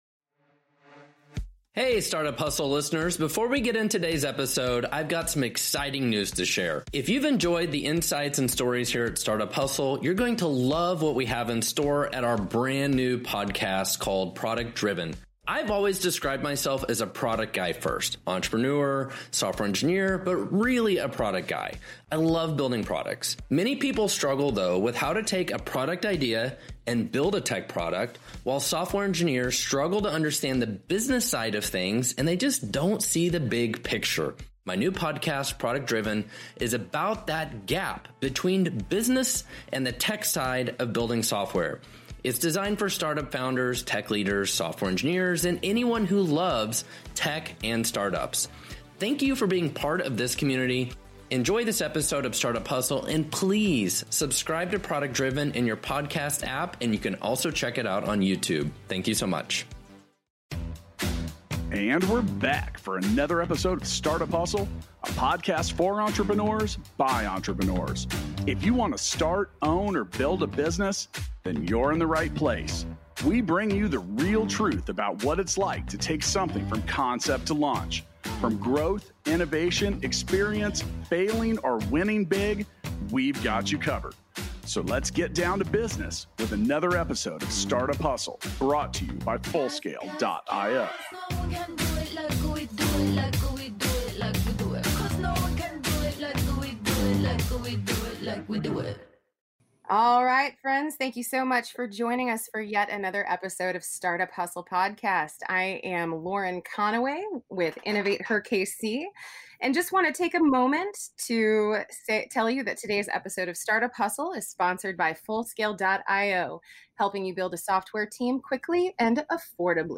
Alissia Canady, attorney, and candidate for Missouri Lt. Governor